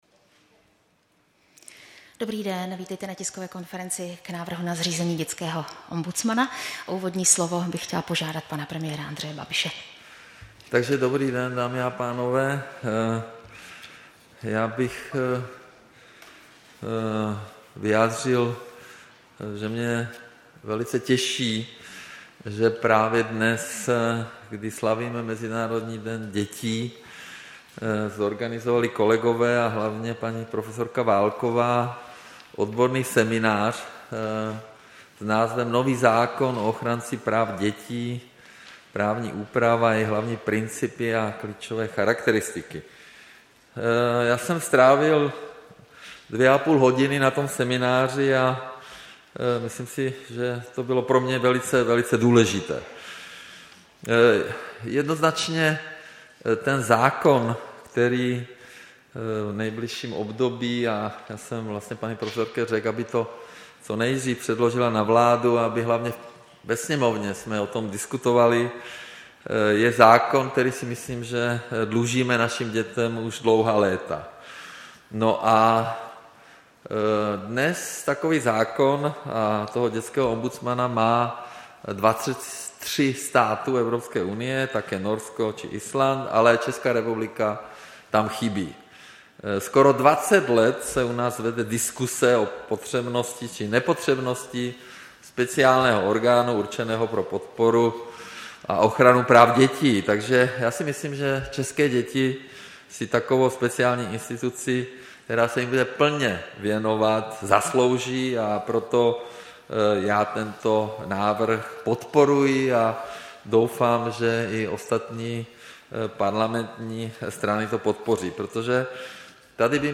Tisková konference k novému zákonu o ochránci práv dětí, 1. června 2020.